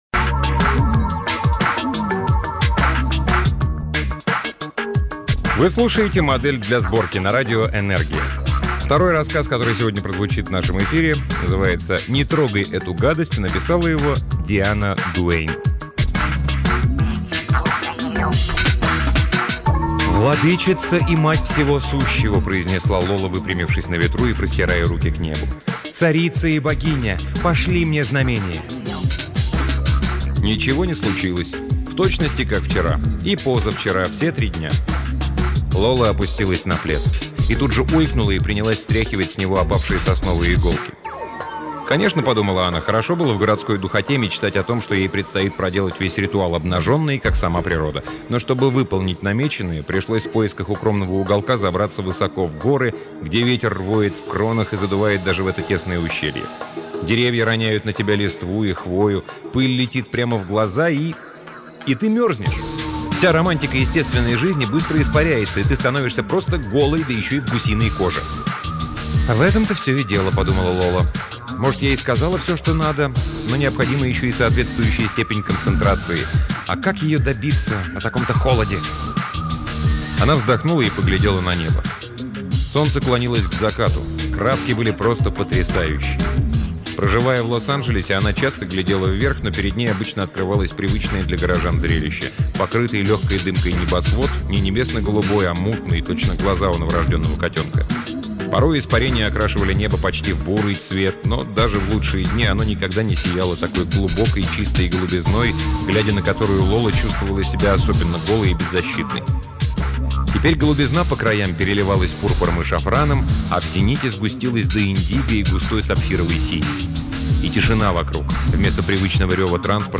Аудиокнига Диана Дуэйн — Не трогай эту гадость